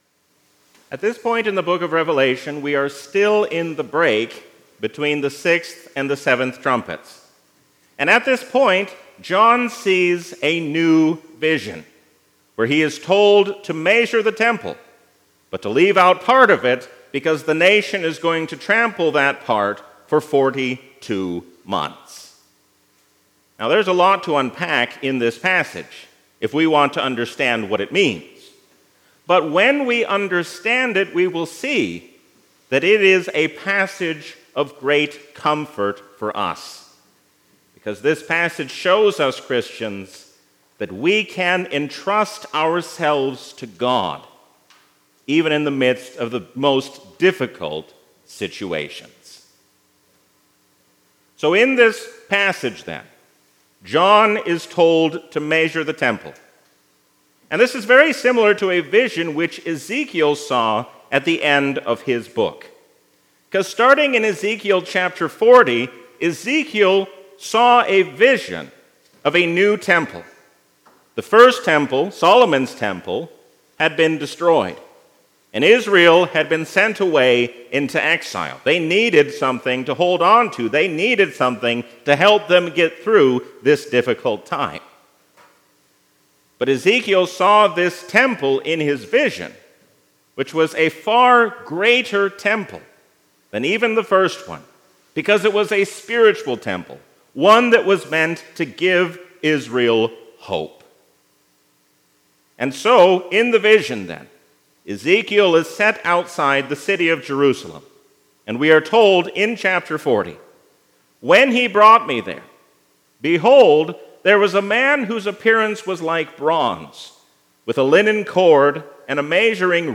A sermon from the season "Trinity 2023." Even though we are trampled underfoot, nothing can take us out of the hand of God.